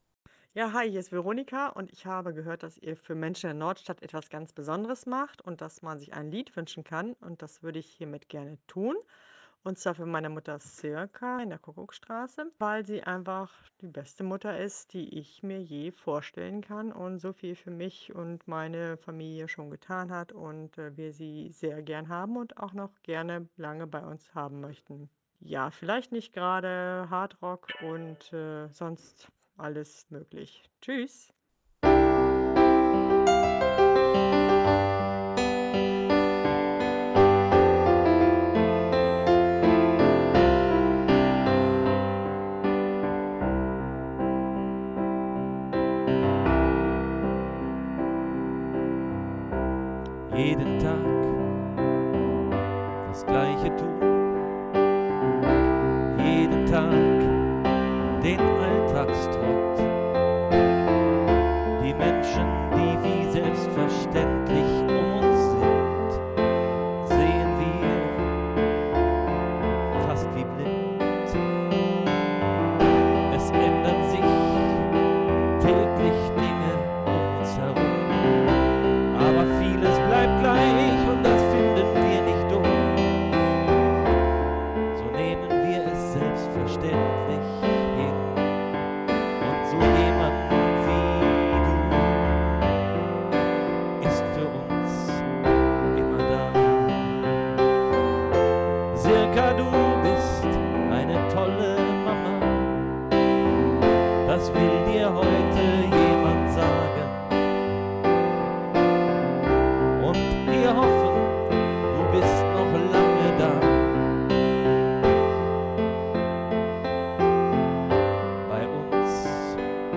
Getragen oder beschwingt?